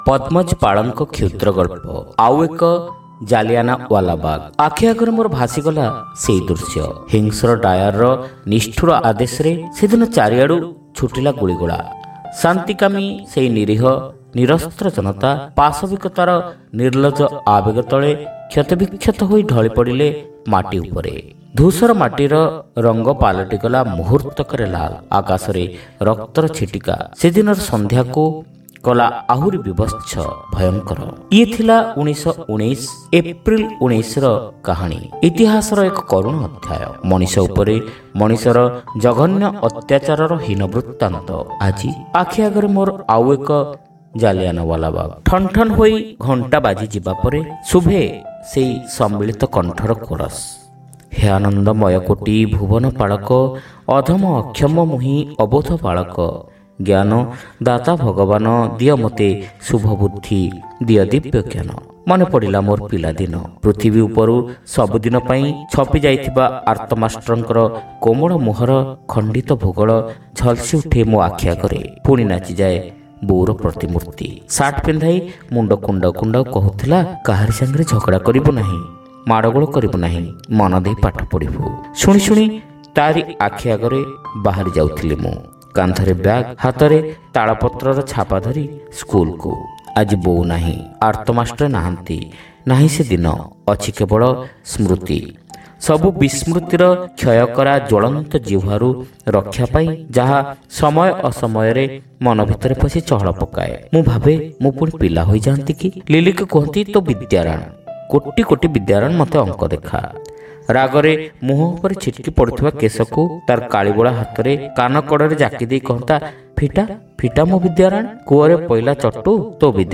Audio Story : Au Eka Jalianawalabag